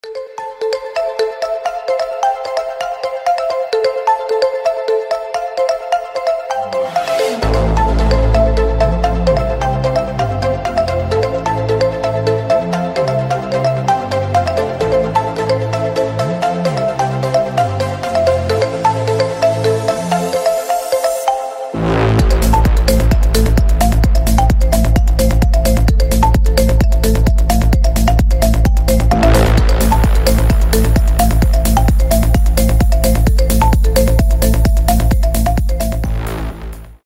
• Качество: 320, Stereo
мелодичные
без слов
psy-trance
ксилофон
транс